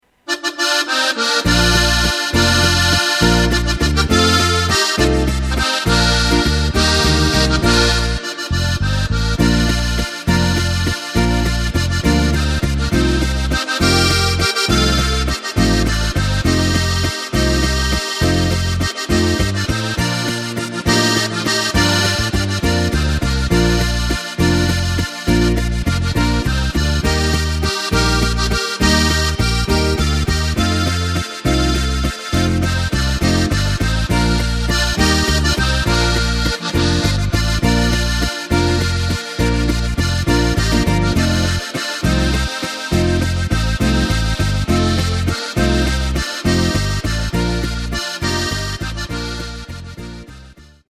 Takt:          3/4
Tempo:         204.00
Tonart:            Bb
Walzer aus dem Jahr 2016!
Playback mp3 mit Lyrics